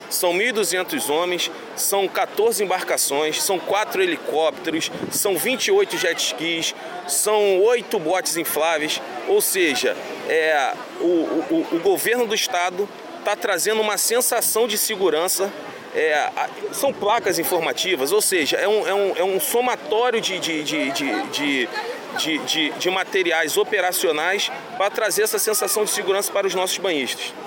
São mais de R$ 3 milhões investidos para dar segurança aos banhistas, como explicou, em entrevista, o Secretário de Estado de Defesa Civil e comandante-geral do Corpo de Bombeiros, Coronel Leandro Monteiro.